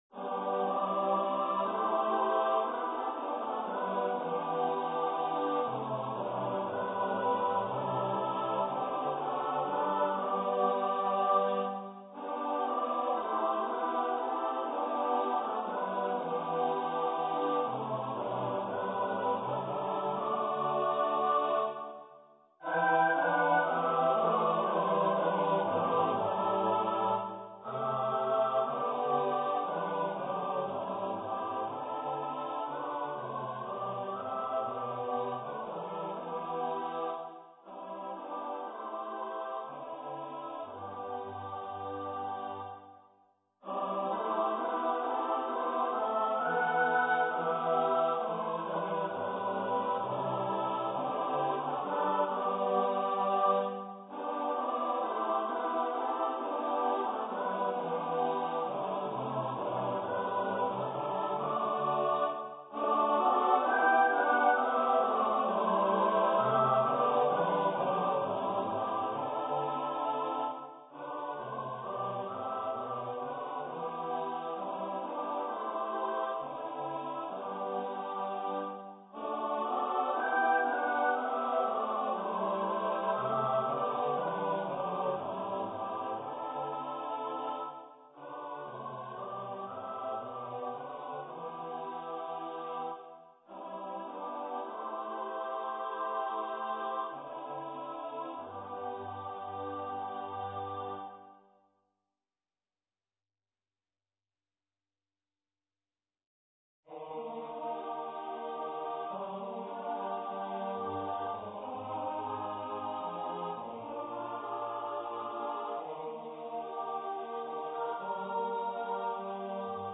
for unaccompanied mixed voice choir
Choir - Mixed voices (SATB)